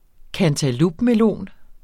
Udtale [ kantaˈlub- ]